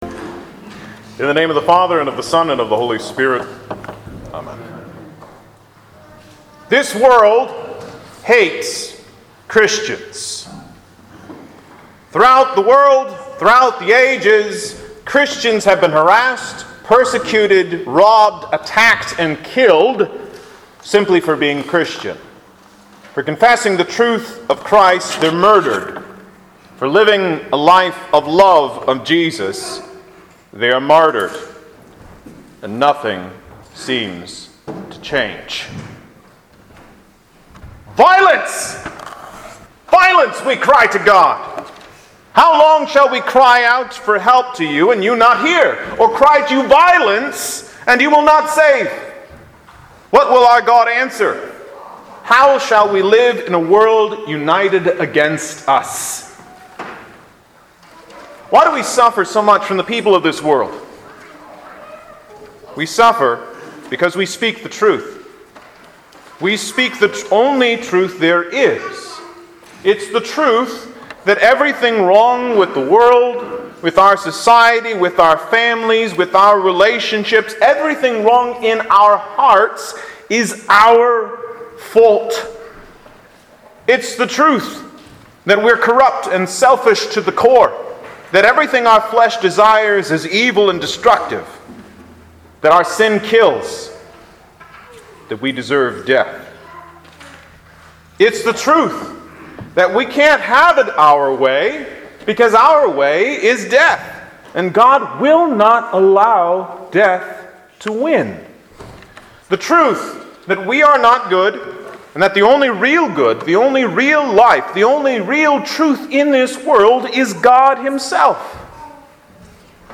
Sermons | Zion Lutheran Church LCMS